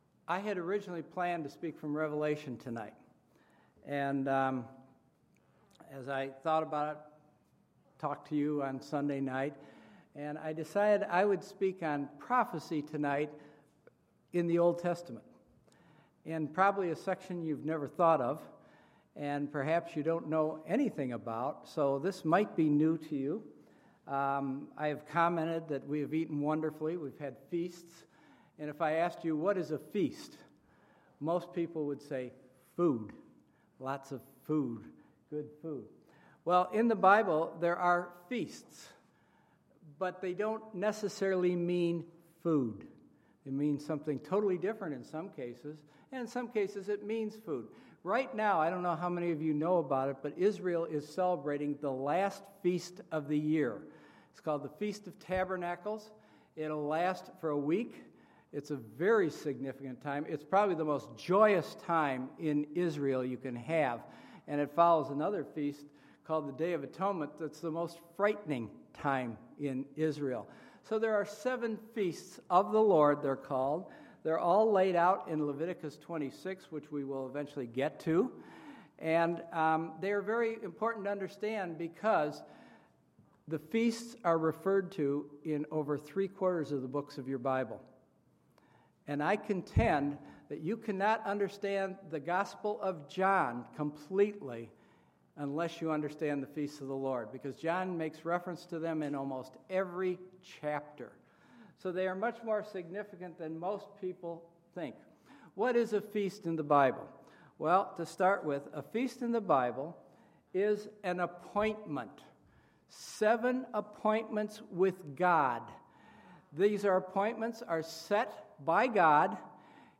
Wednesday, September 30, 2015 – Missions Conference Wednesday Evening Service
Sermons